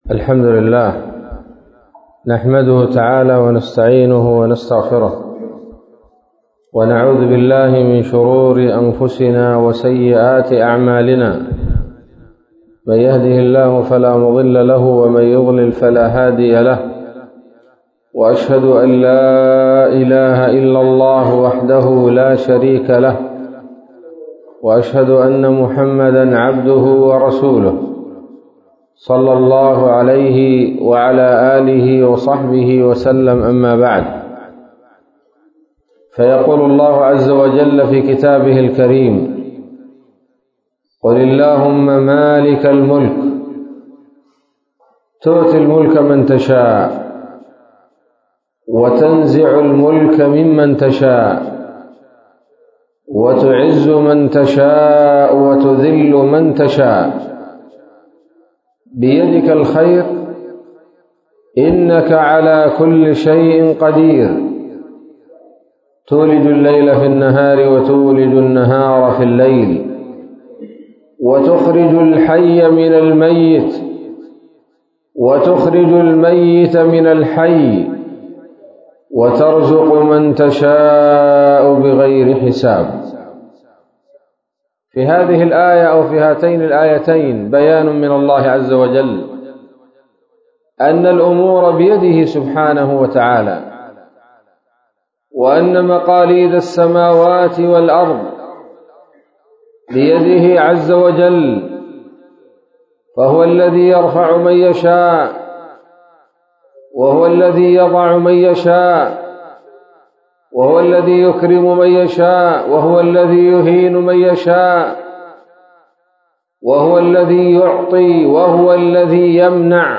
كلمة قيمة بعنوان:((الرفعة الصحيحة
مسجد السوق بمنطقة الخوخة